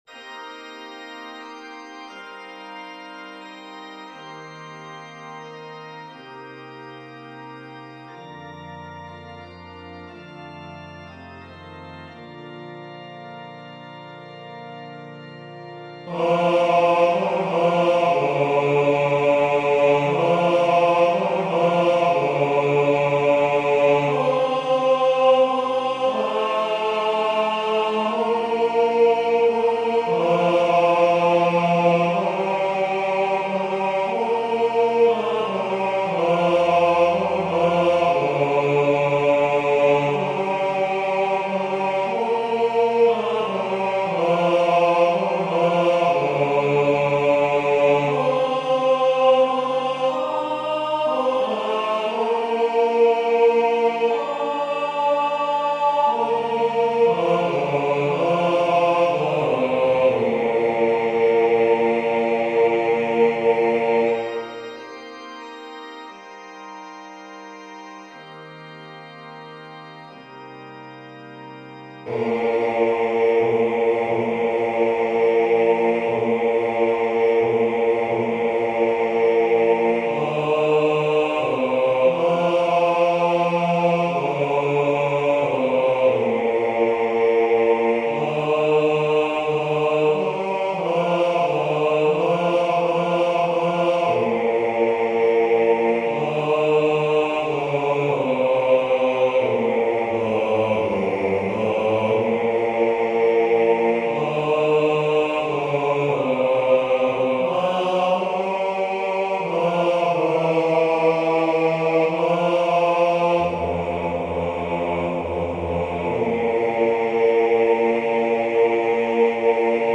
Silent_Night_bass.mp3